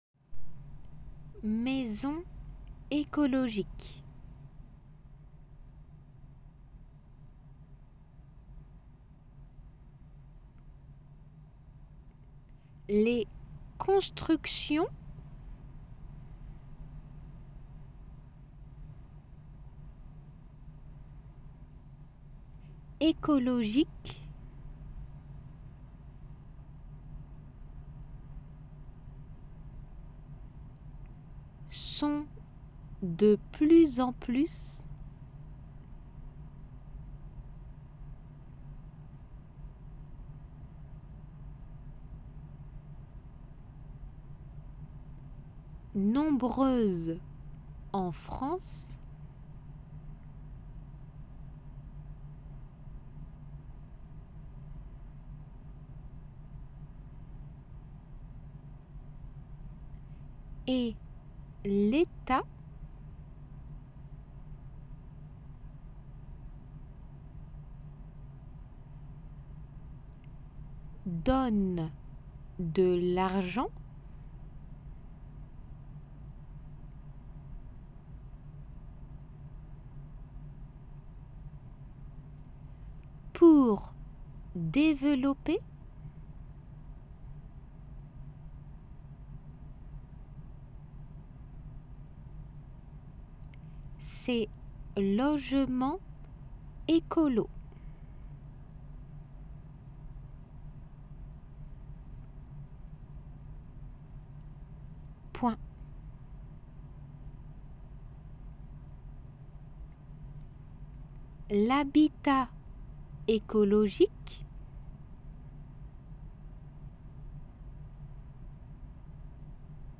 仏検2級11月に向けて準備 8ー書き取り音声
デイクテの速さで